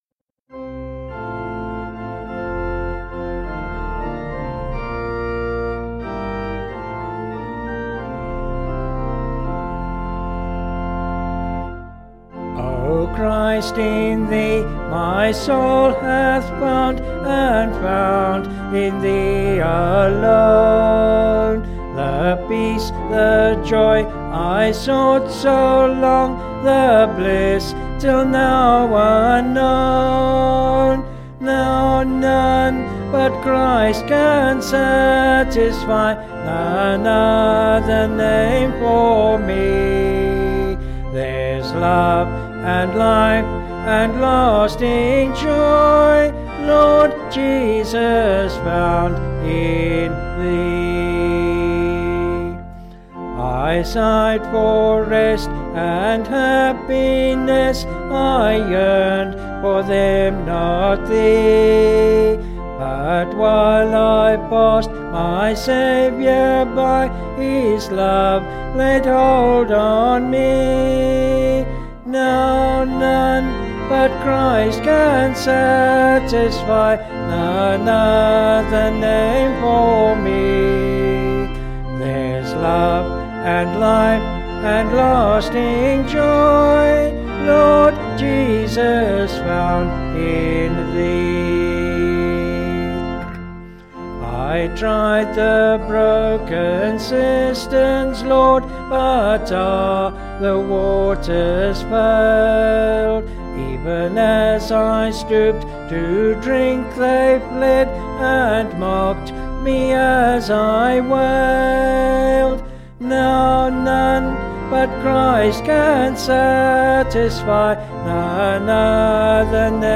Vocals and Organ   265.9kb Sung Lyrics